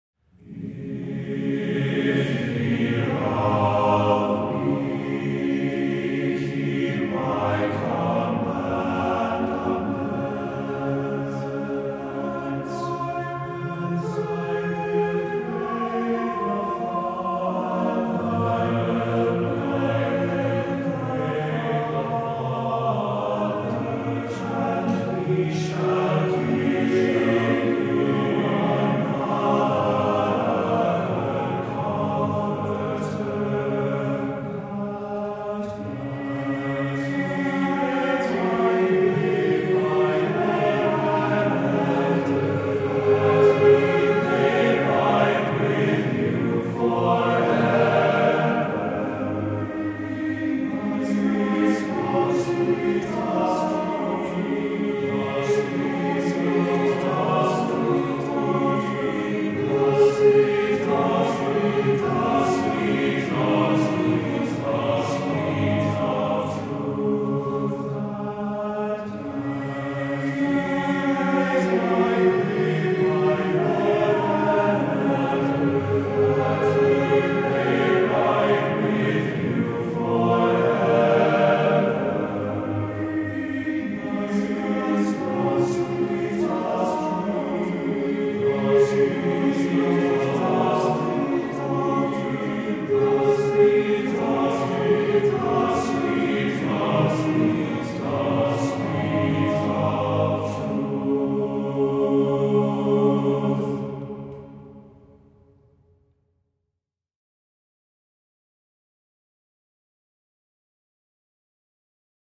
Voicing: TTBB a cappella